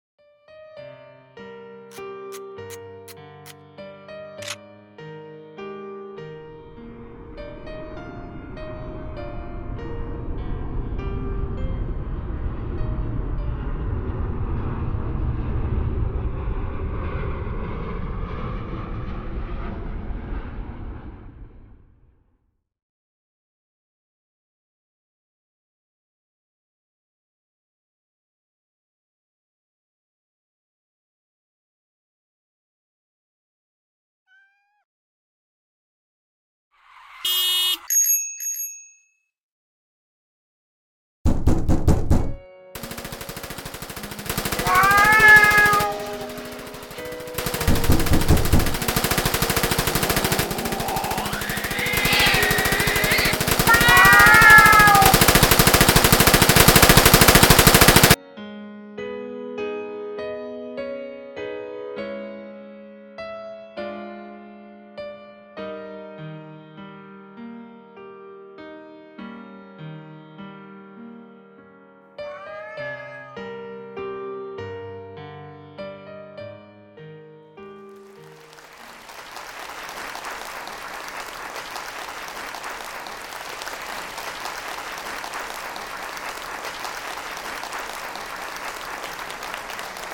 【声劇】.pubblico(alone)